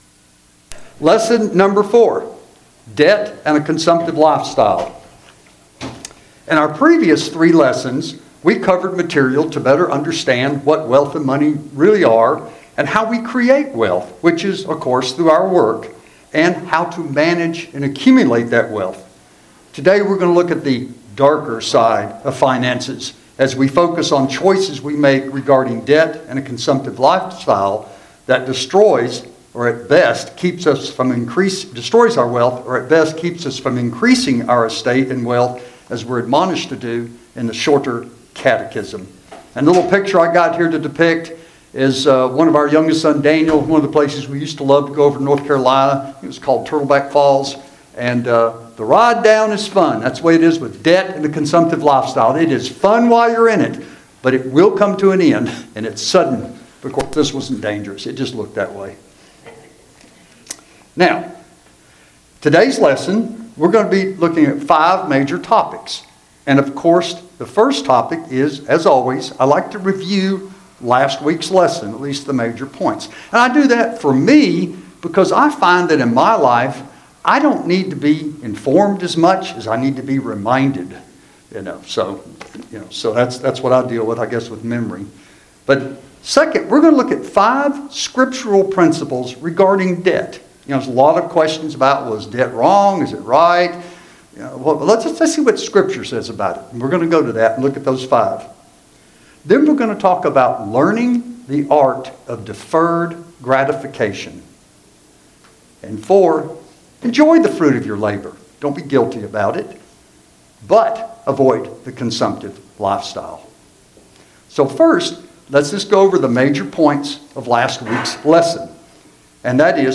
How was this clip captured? Service Type: Sunday School Handout